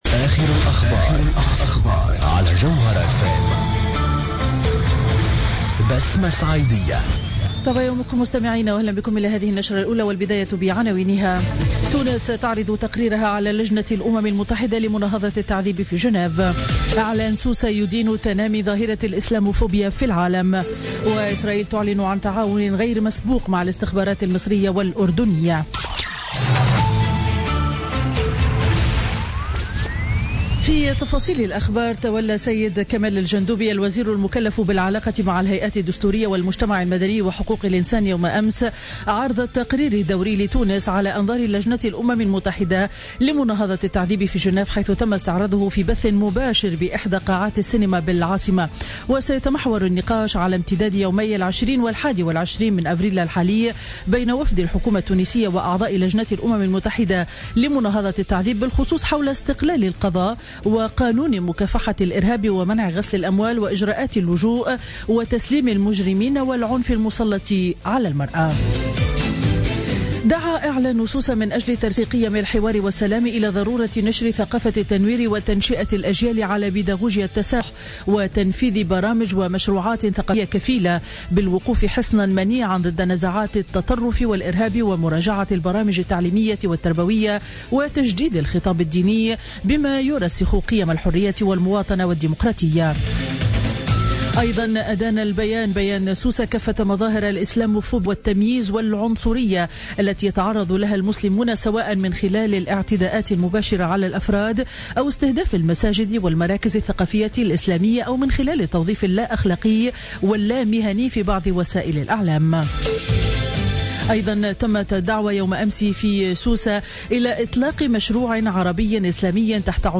نشرة الأخبار ليوم الخميس 21 أفريل 2016